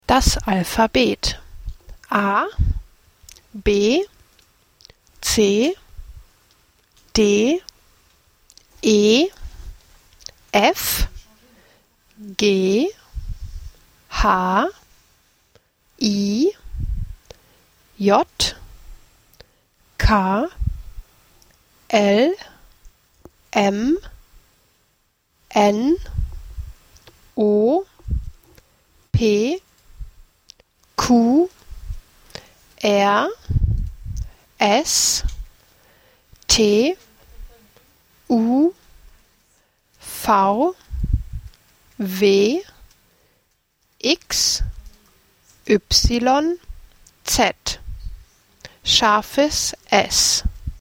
• J is pronounced like English “y”.
• L is pronounced slightly different from English with the tongue touching the back of the teeth.
• ß is pronounced like a double “ss”.
alphabet-german.mp3